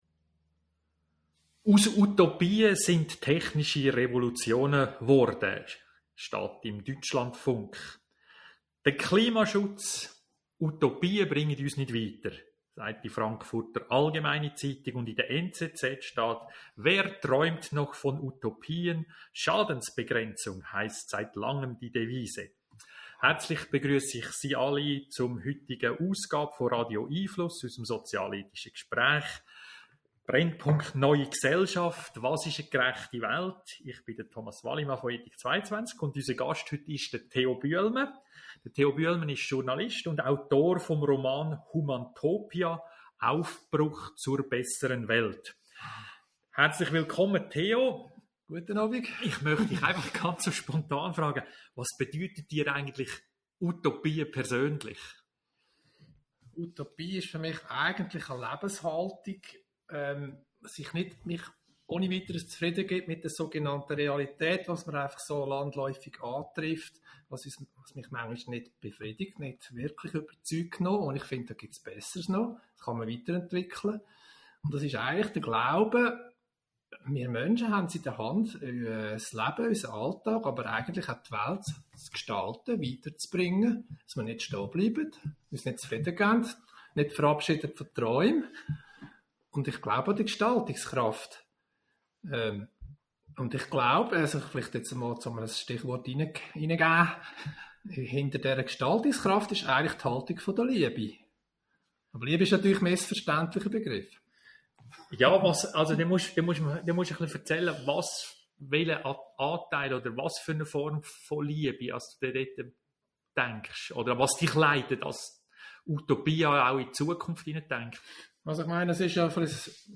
Radio🎙einFluss findet jeden Mittwochabend von 18.30 - 19 Uhr statt.
Bleiben sie über die kommenden Radio🎙einFluss Audio-Gespräche informiert!